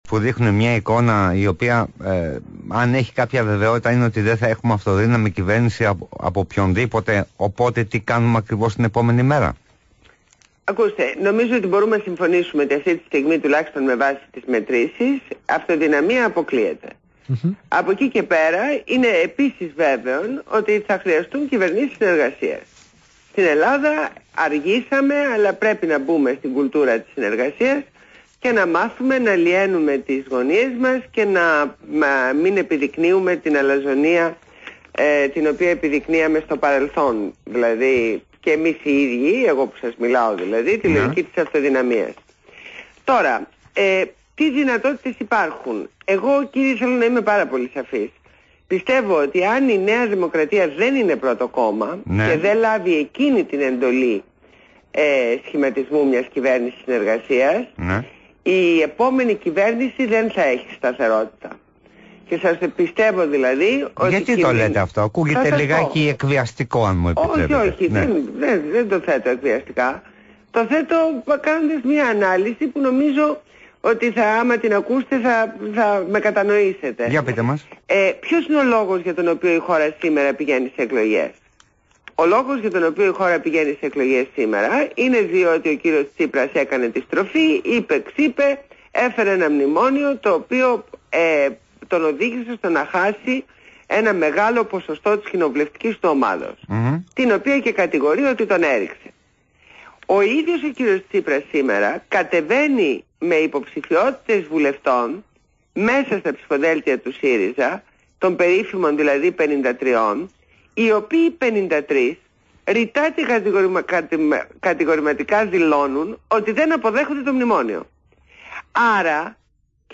Συνέντευξη στο ραδιόφωνο ΒΗΜΑfm